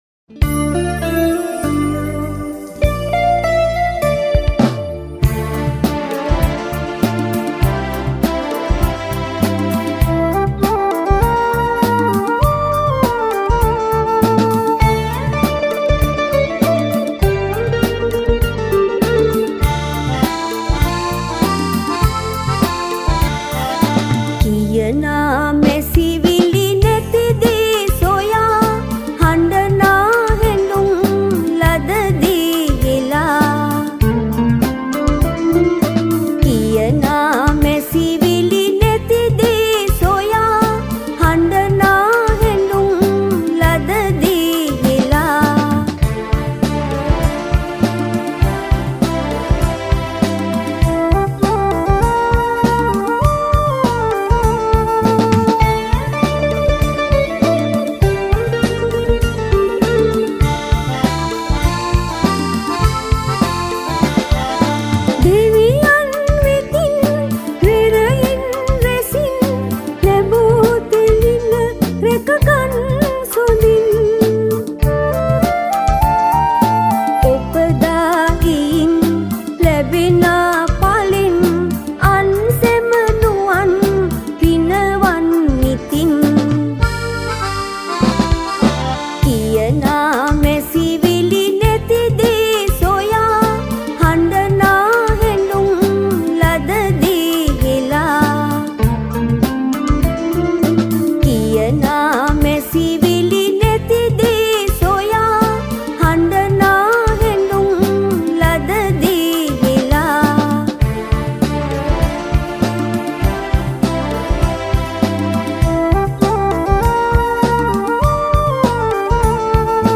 at the Sage Studio, Sri Lanka